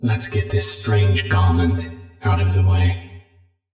TakeOffHazmat.ogg